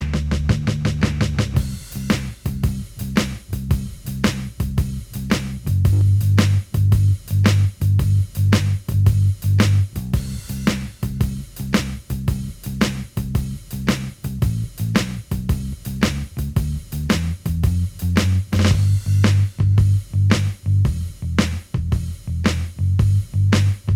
Minus Electric Guitar Soft Rock 4:26 Buy £1.50